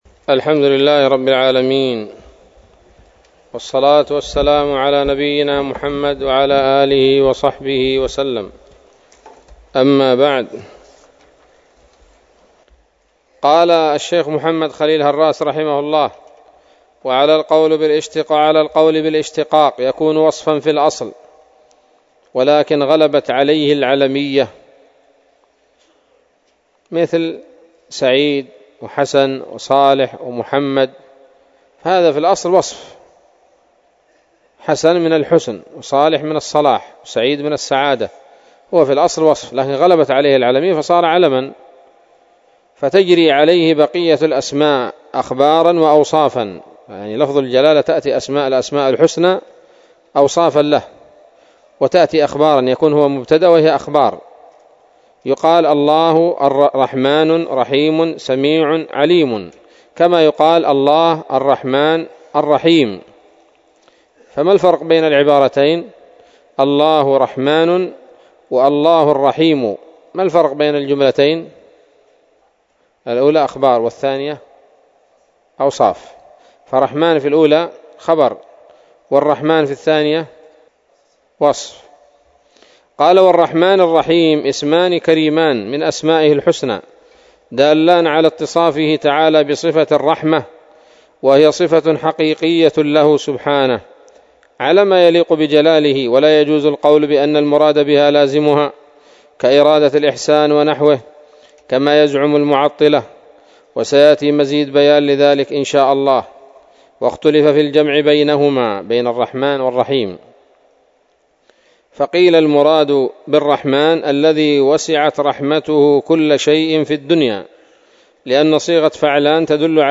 الدرس السادس من شرح العقيدة الواسطية للهراس